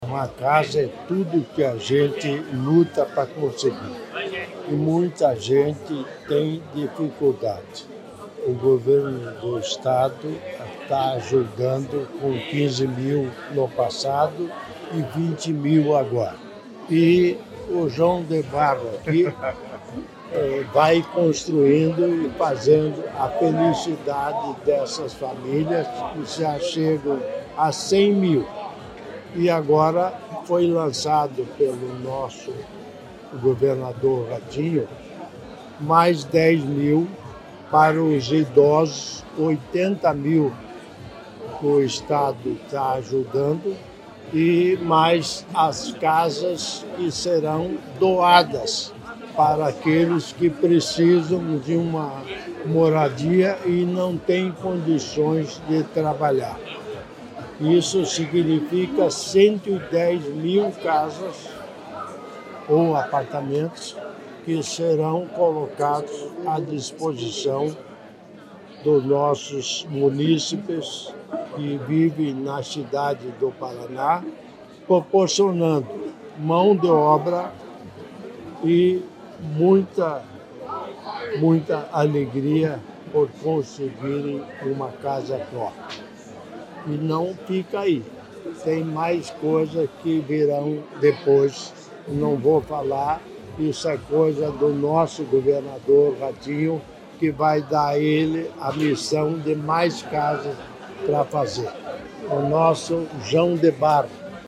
Sonora do governador em exercício Darci Piana sobre a entrega de residencial com 128 apartamentos para atender famílias de Londrina